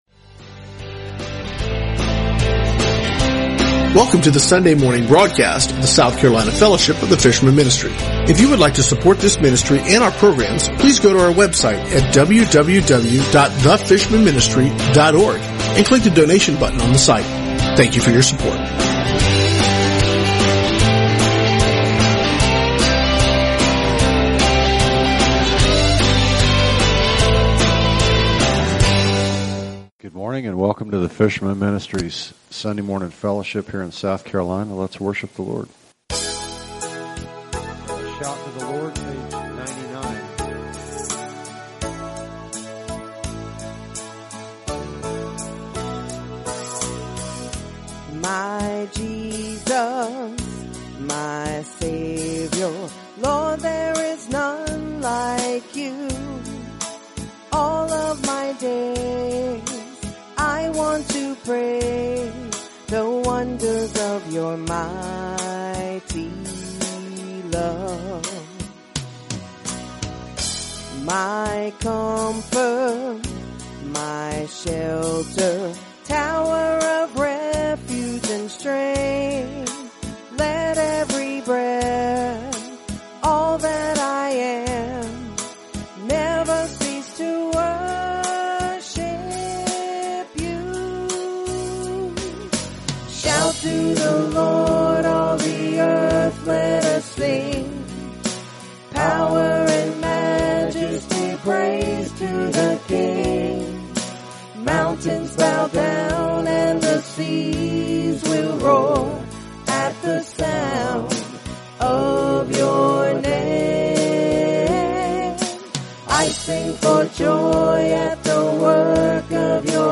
Sunday South Carolina Church Service 03/26/2017 | The Fishermen Ministry